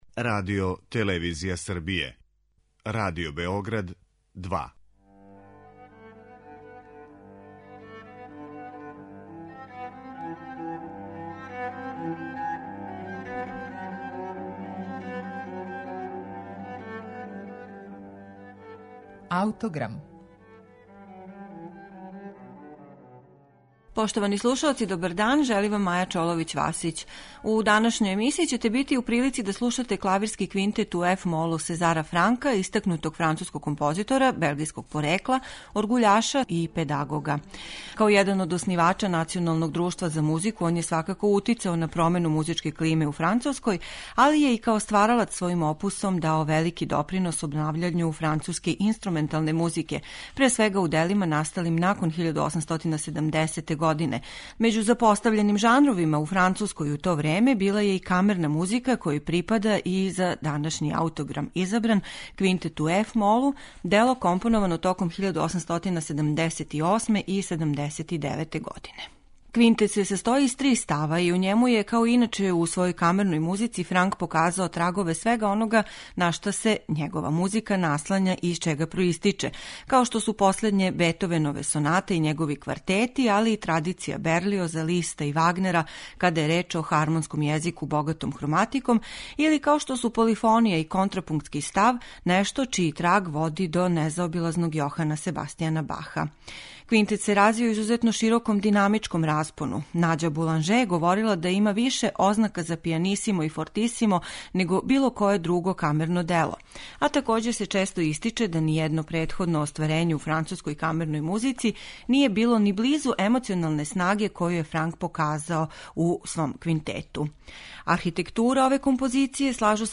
Сезар Франк - Клавирски квинтет
Важна карактеристика Франковог музичког стила - принцип мотивског повезивања ставова - присутна је и у Квинтету који ћете данас слушати у извођењу Свјатослава Рихтера и Гудачког квартета Бородин.